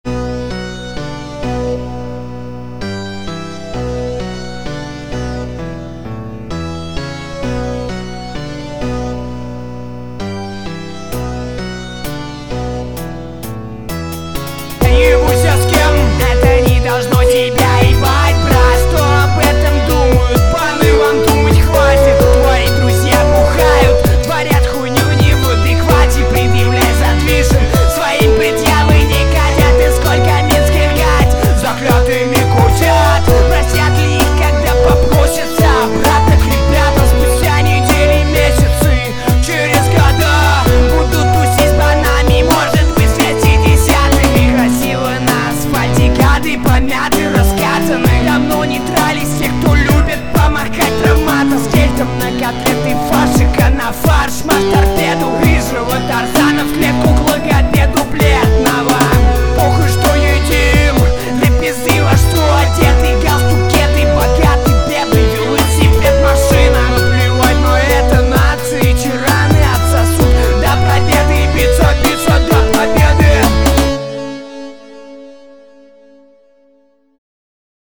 два трека, записанные как всегда в гараже/квартире/подвале/улице, без усилителей, микшеров и прочего ненужного))))))) стафа. только нетбук, наушники с микрофоном, аля контрстрайк ))) (250 руб). прогрмамы — LMMS + Ardour